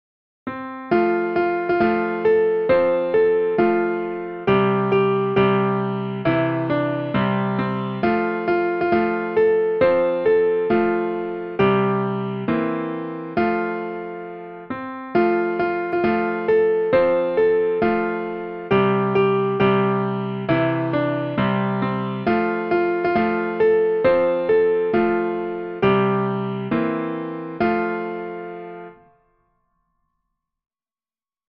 a children's song and dance
for piano